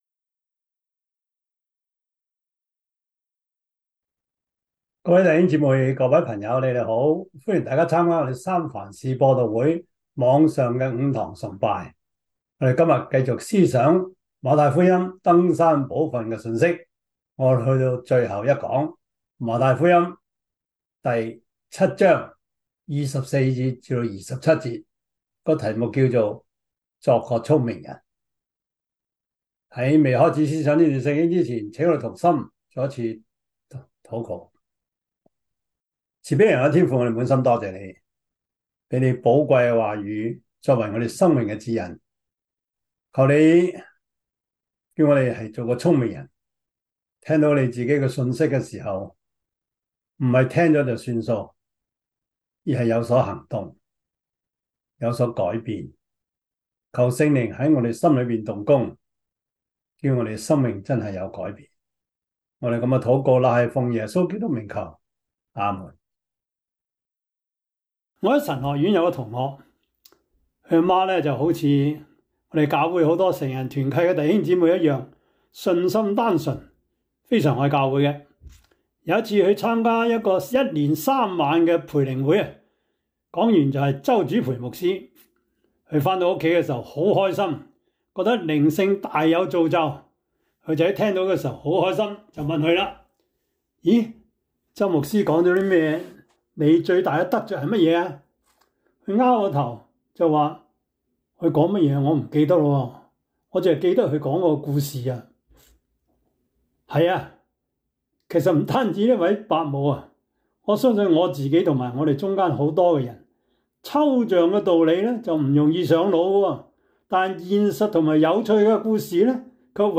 Service Type: 主日崇拜
Topics: 主日證道 « 顛覆文化的生活方式 基礎神學 – 第七課 »